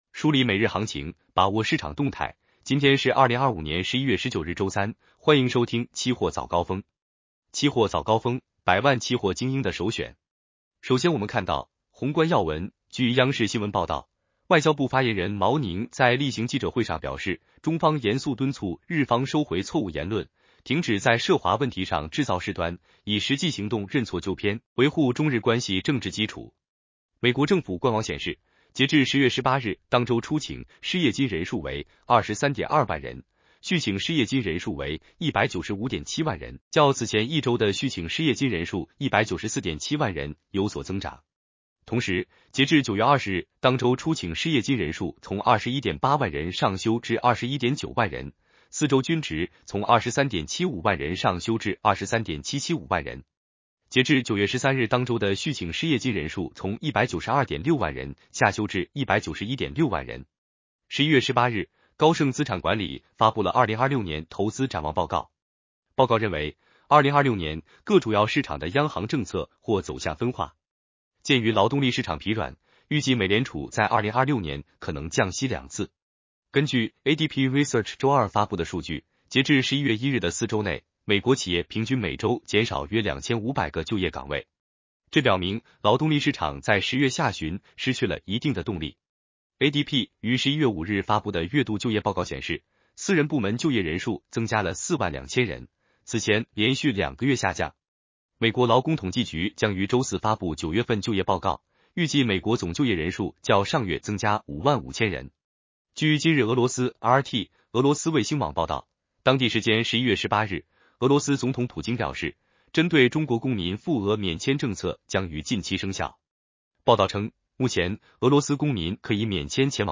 期货早高峰-音频版
期货早高峰-音频版 男生普通话版 下载mp3 热点导读 1.